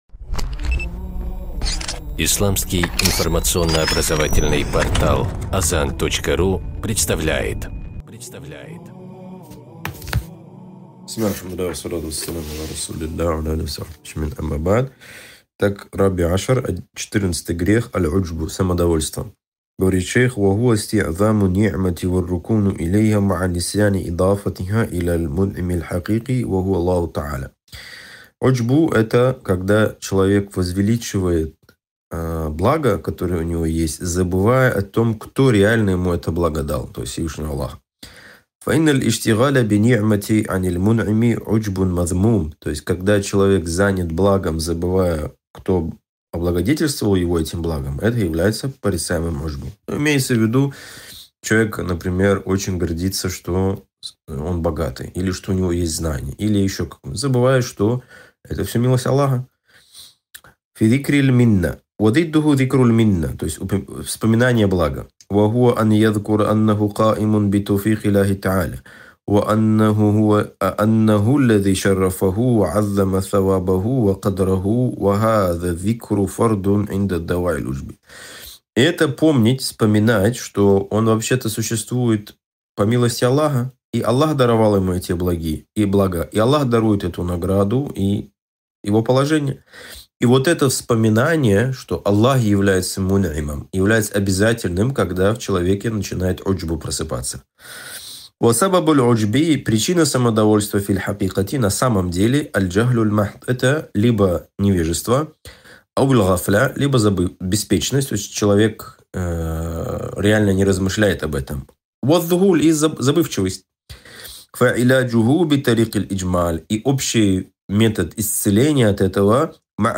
Цикл уроков: «Харам и халяль» по книге «Хазр валь-Ибаха»